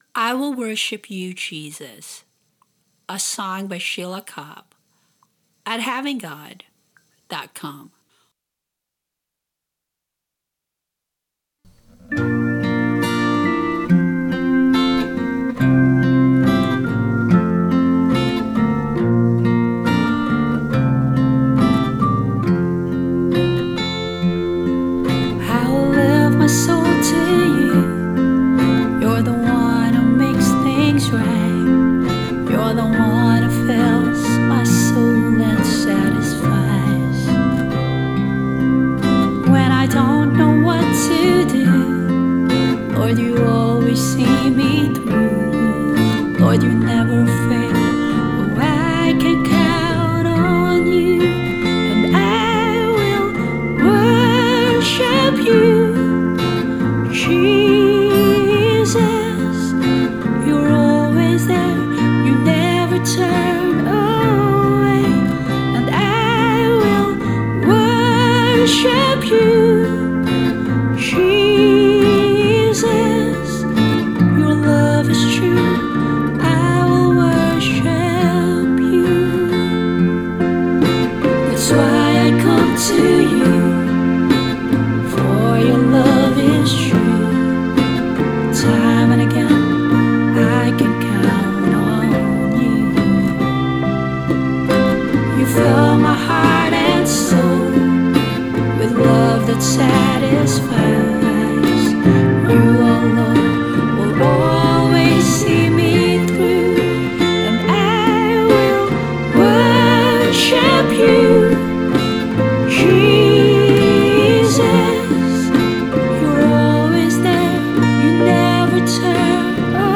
Vocals, guitar, bass and bongos
Organ, keyboard and strings